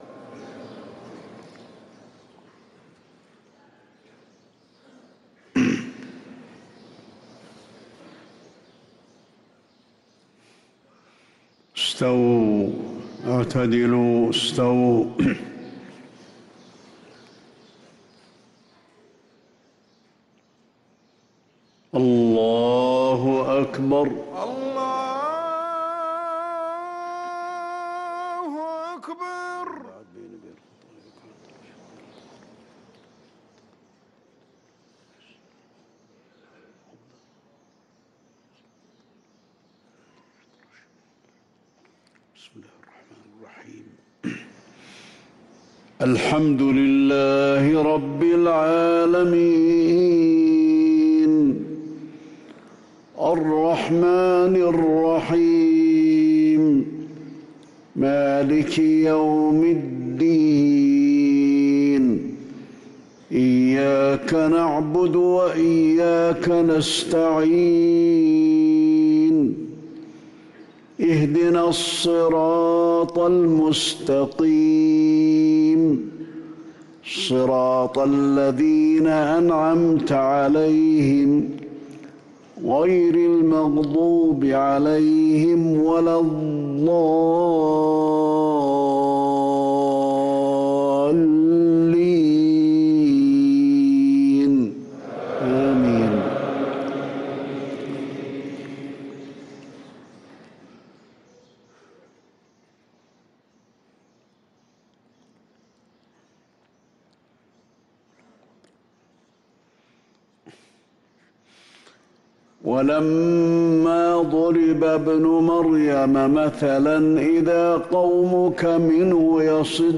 صلاة الفجر للقارئ علي الحذيفي 24 ربيع الآخر 1445 هـ
تِلَاوَات الْحَرَمَيْن .